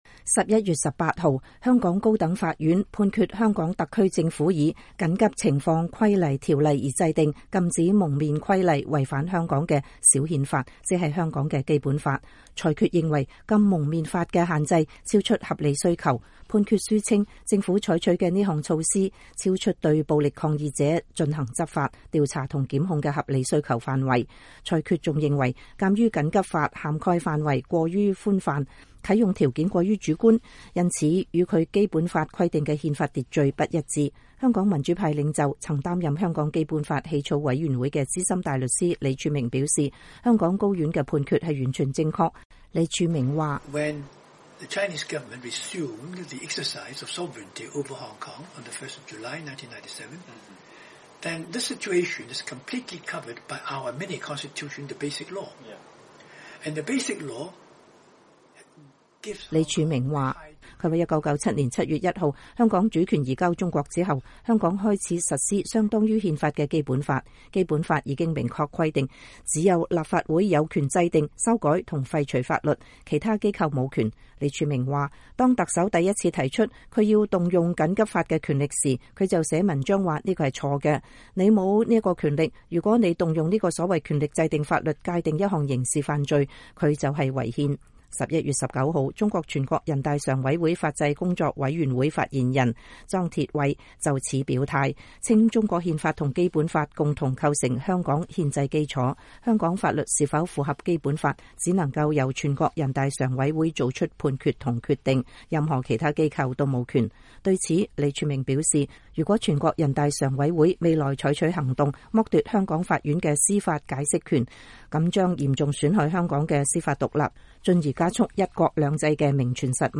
專訪李柱銘 - 香港步入警察政府高院判《禁蒙面法》違憲全正確